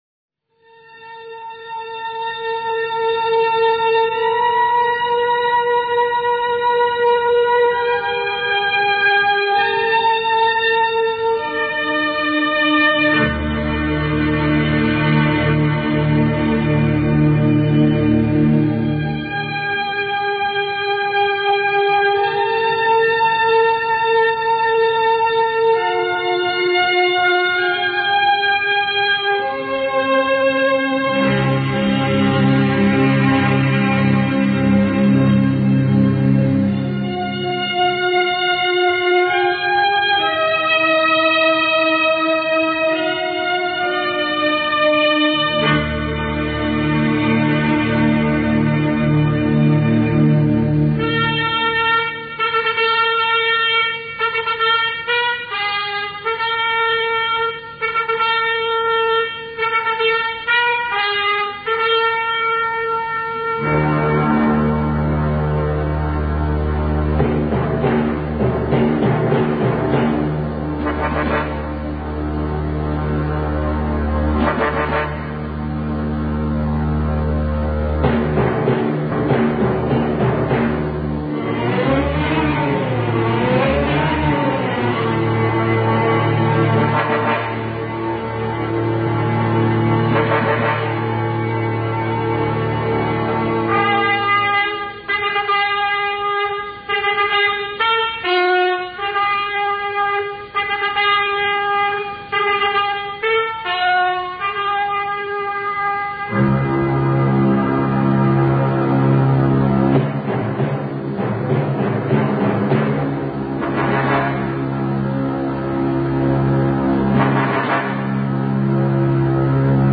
موسیقی دفاع مقدس